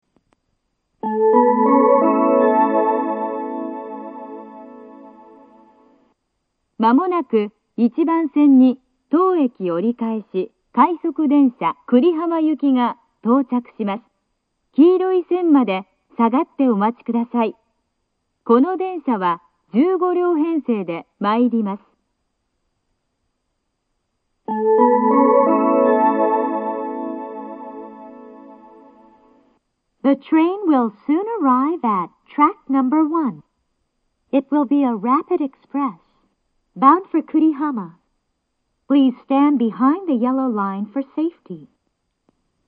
この駅は禁煙放送が流れていて、それが被りやすいです。
１番線接近放送 折り返し快速久里浜行（１５両）の放送です。